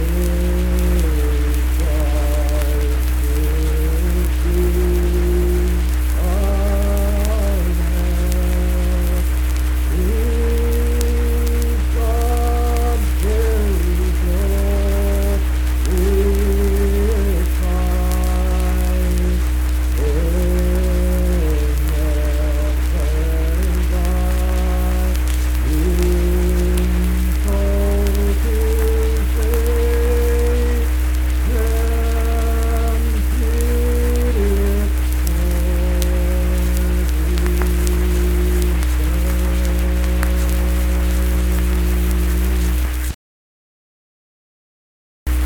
Unaccompanied vocal music
Hymns and Spiritual Music
Voice (sung)
Webster County (W. Va.)